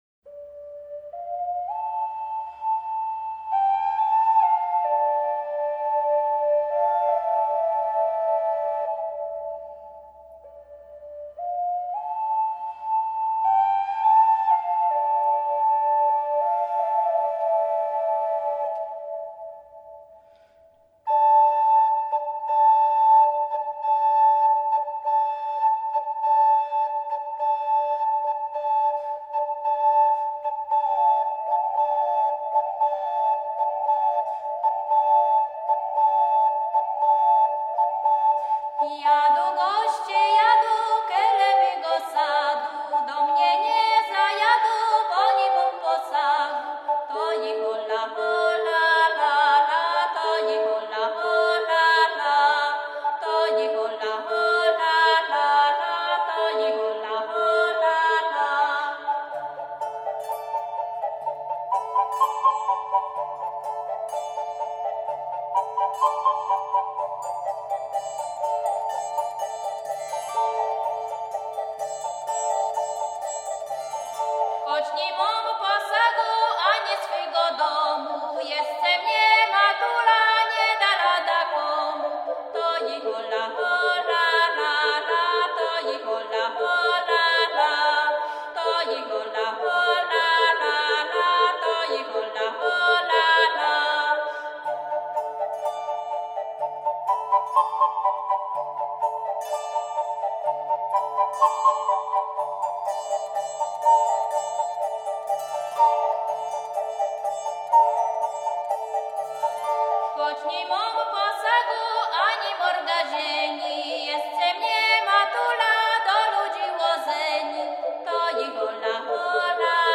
Pieśni kurpiowskie
Są to tzw. p i e ś n i l e ś n e, wykonywane w wolnym tempie, przy dużym natężeniu głosu i tak: "coby echo łodpoziedało".